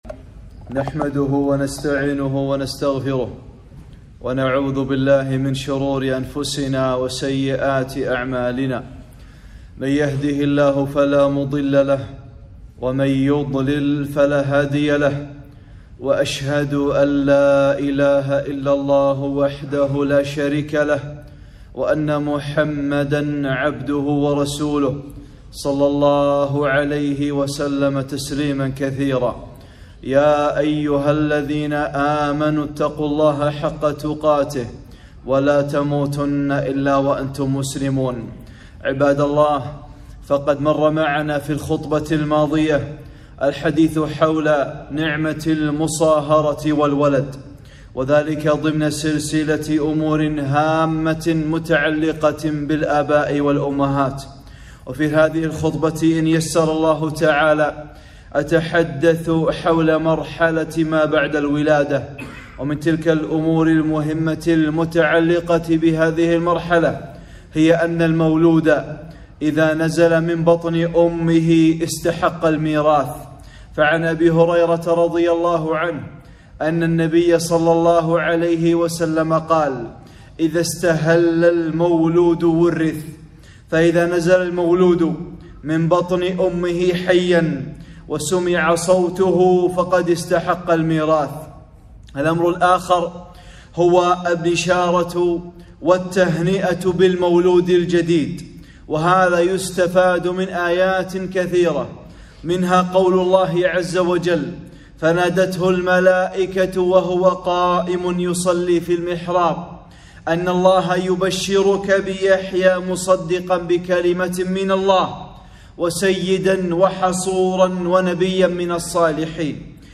(11) خطبة - التهنئة بالمولود | أمور هامة متعلقة بالآباء والأمهات